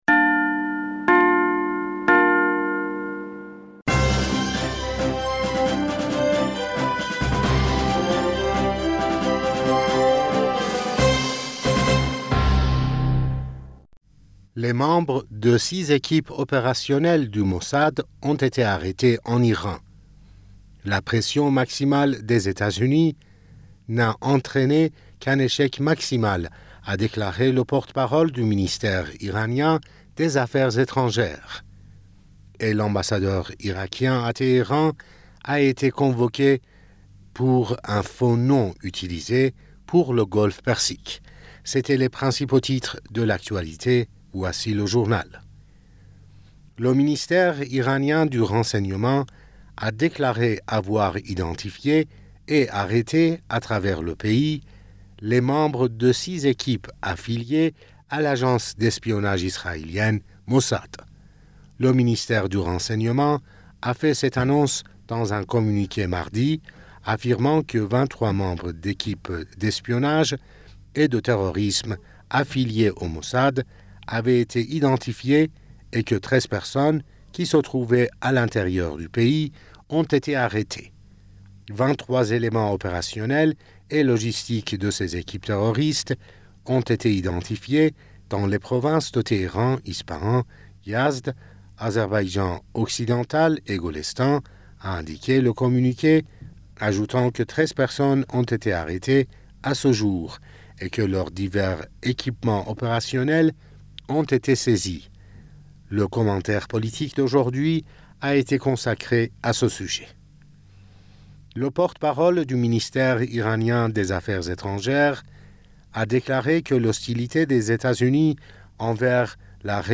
Bulletin d'information du 11 Janvier